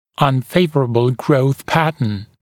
[ʌn’feɪvərəbl grəuθ ‘pætn][ан’фэйвэрэбл гроус ‘пэтн]неблагоприятный тип роста